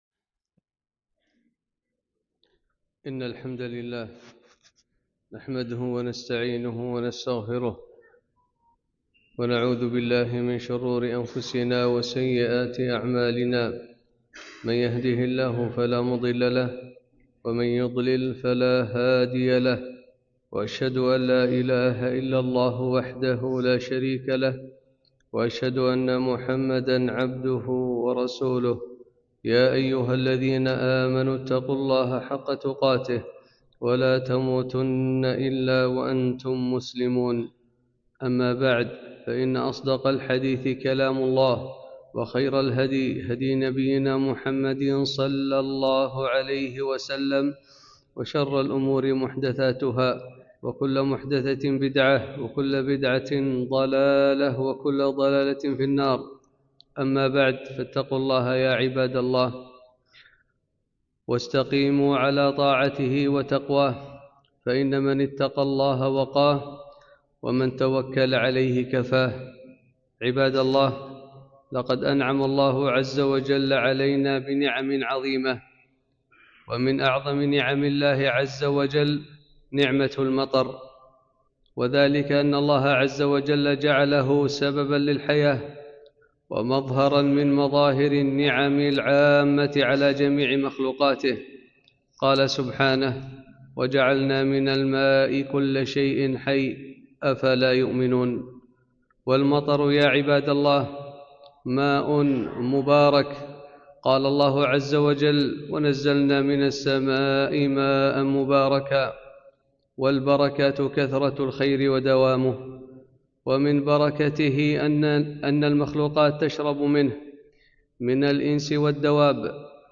خطبة - نعمة المطر